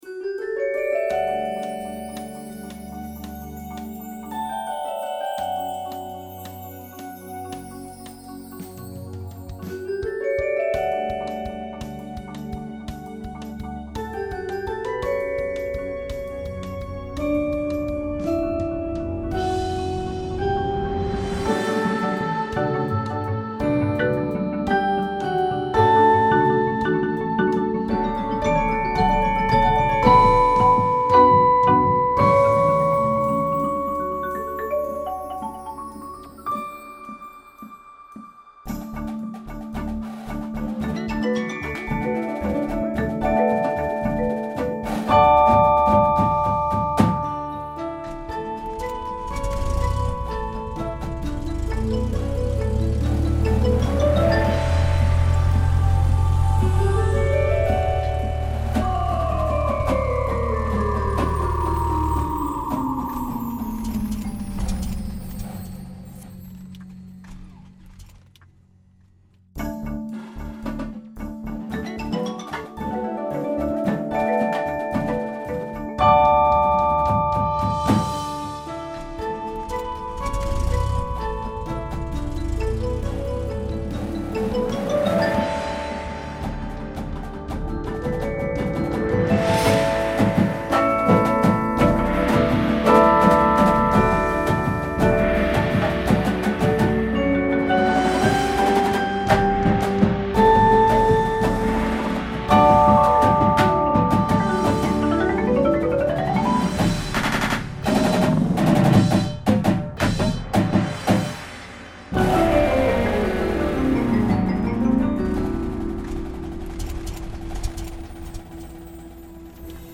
• Marching Snare Drum
• Marching Bass Drum (4 and 5 drum parts available)
Front Ensemble
• One synthesizer part (Mainstage patches included)
• Marimba 1/2
• Glockenspiel/Xylophone
• Vibraphone 1/2
• Bass Guitar